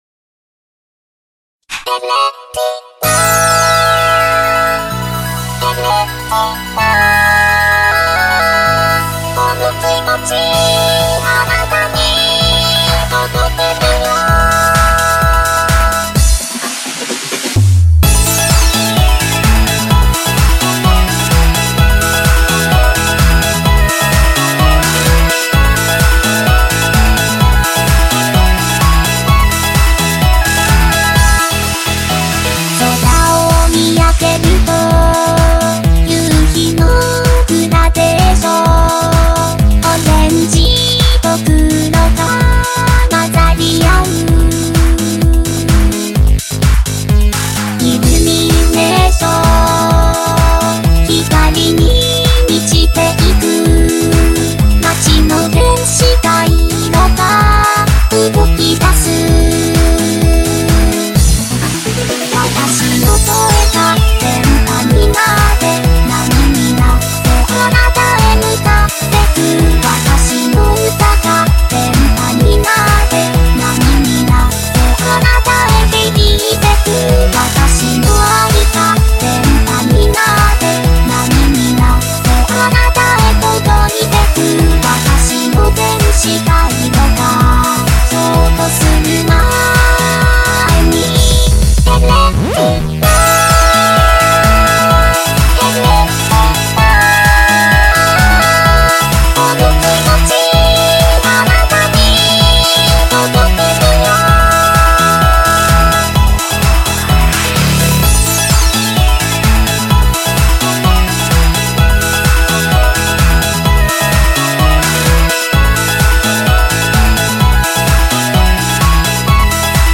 BPM64-128
Audio QualityCut From Video